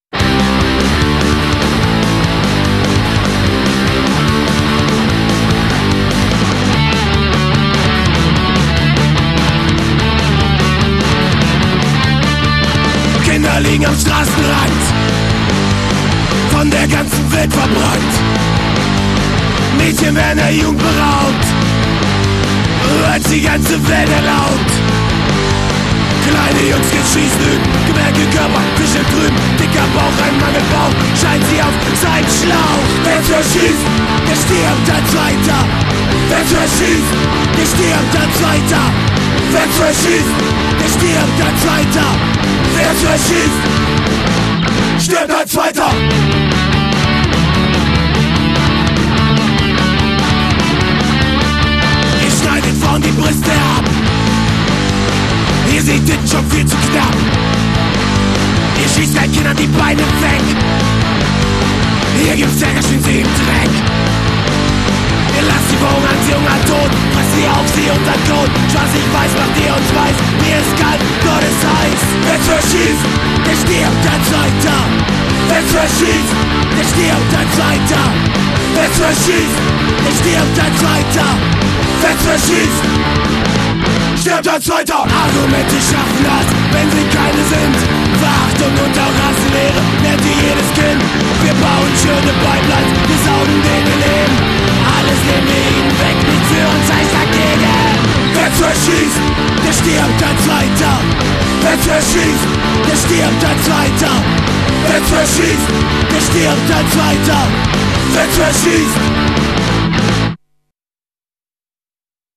Alte Demoaufnahmen: